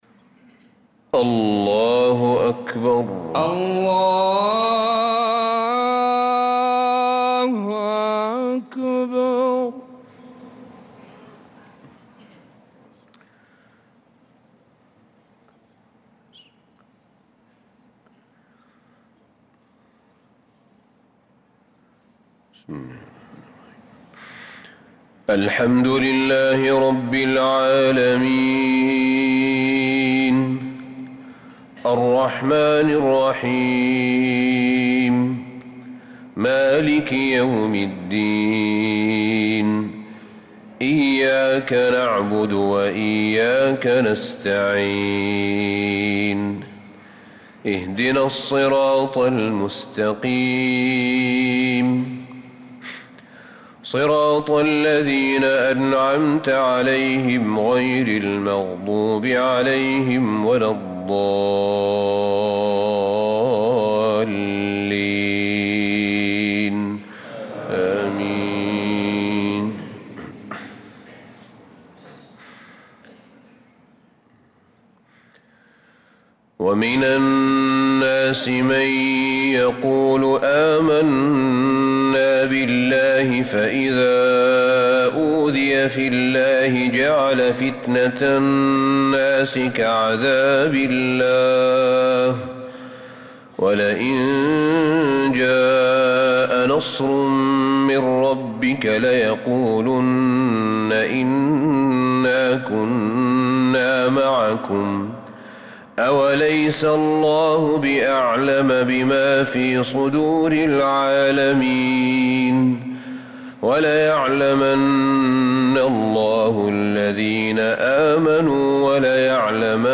صلاة الفجر للقارئ أحمد بن طالب حميد 13 رجب 1446 هـ
تِلَاوَات الْحَرَمَيْن .